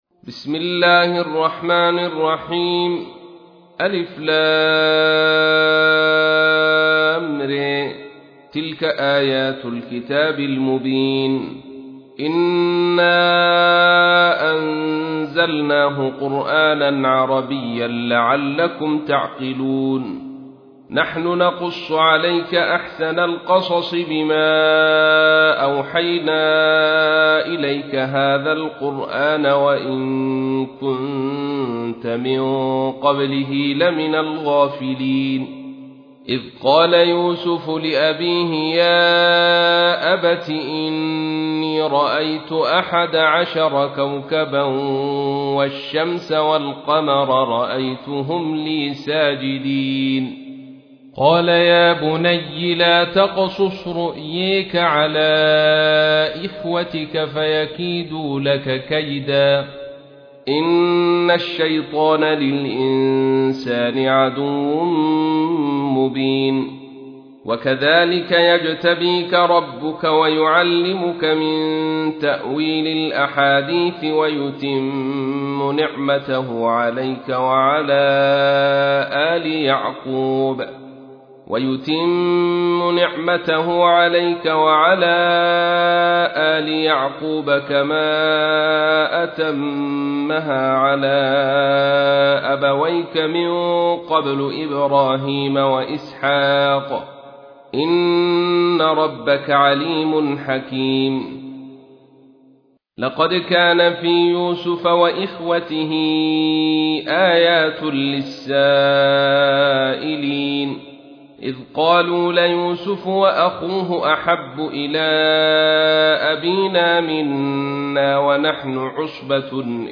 تحميل : 12. سورة يوسف / القارئ عبد الرشيد صوفي / القرآن الكريم / موقع يا حسين